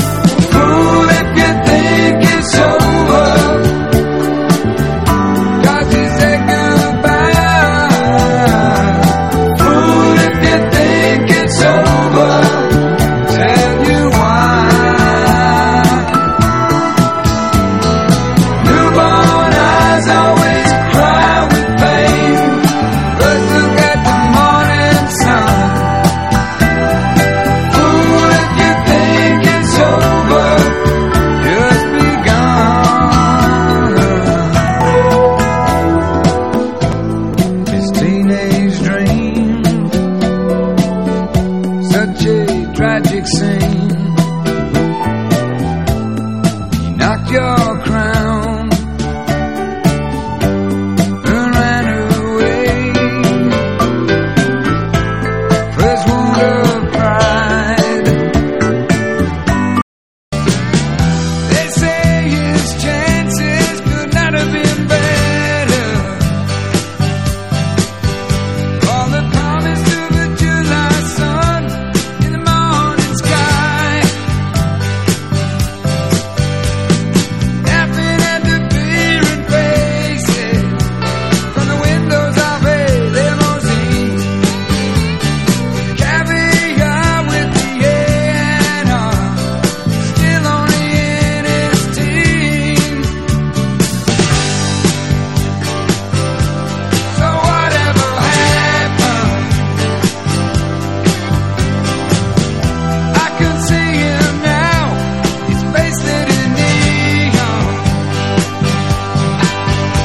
EASY LISTENING / VOCAL / JIVE / OLDIES / RHYTHM & BLUES
ジャイヴィンなDJにも激推しの嬉し泣き＆泣き躍りチューン